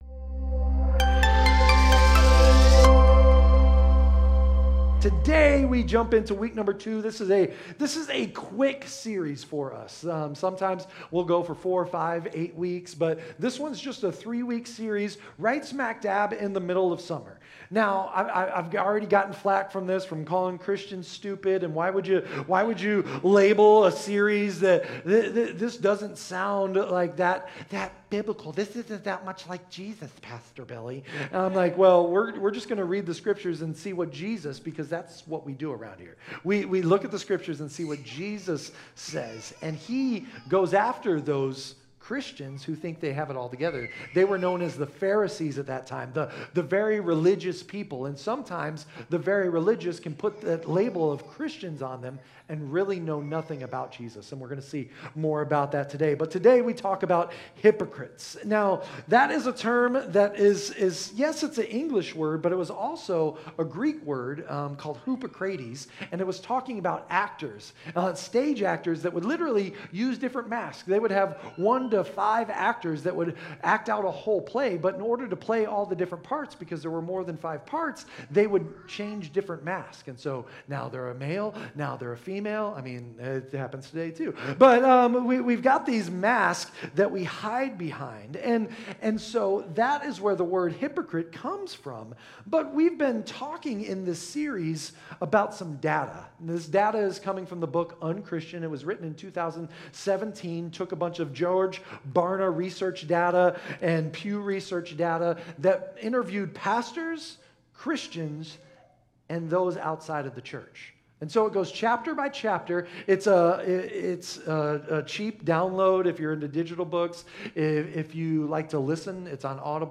In this eye-opening sermon, we explore a startling statistic: 85% of people view Christians as hypocritical, even though 84% of them personally know someone who claims to be a Christian.